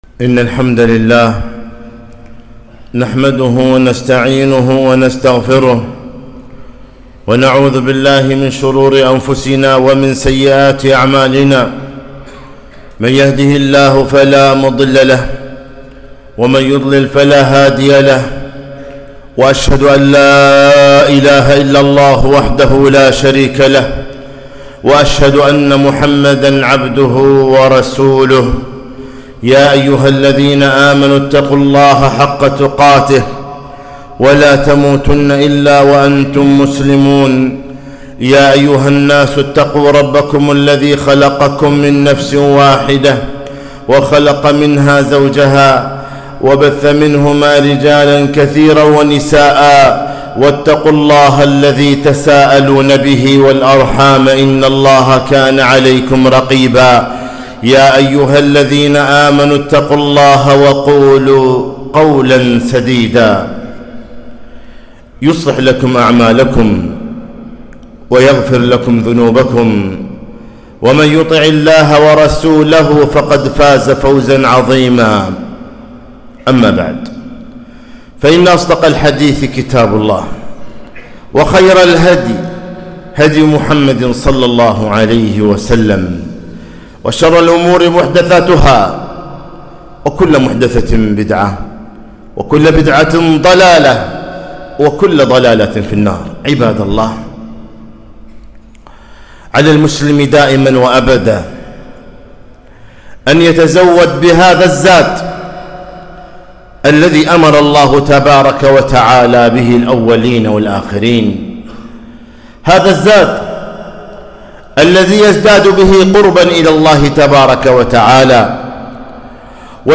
خطبة - اتق الله حيثما كنت